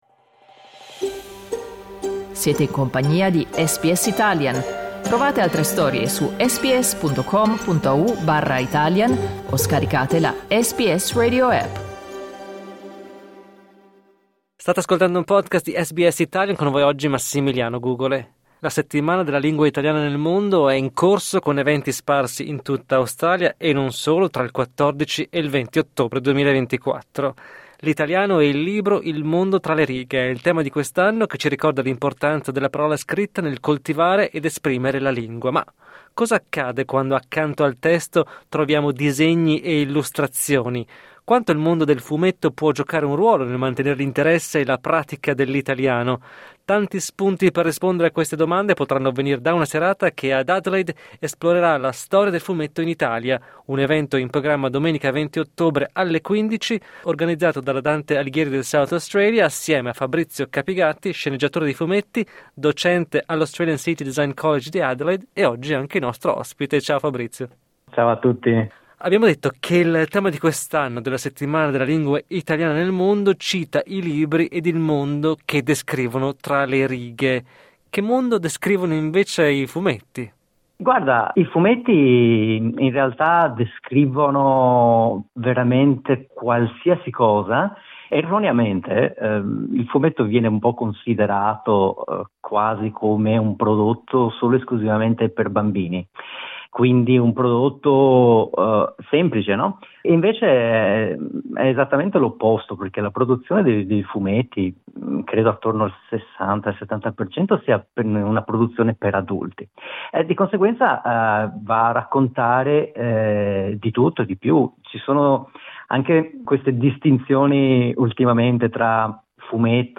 ai microfoni di SBS Italian